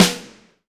SBSNARE.wav